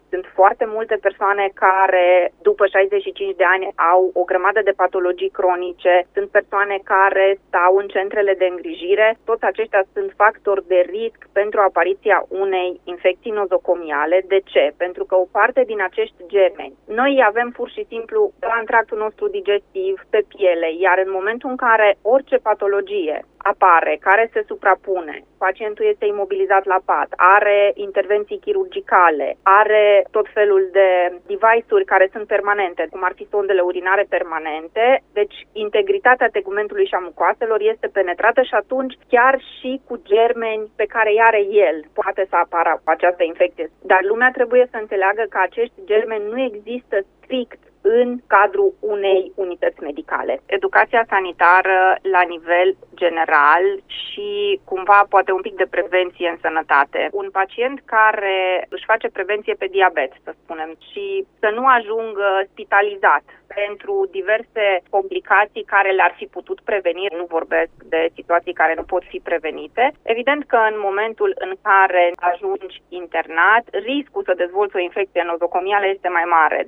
Despre riscurile de a fi infectat în spital, reducerea și controlul infecțiilor nosocomiale a vorbit luni, 6 octombrie, la Radio Cluj